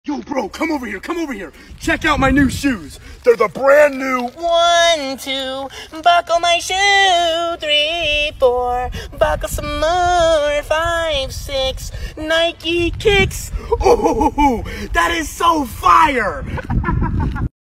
Un fragmento de la canción infantil clásica. Divertido y nostálgico, usado para bromas de conteo o momentos tontos.
12-buckle-my-shoe.mp3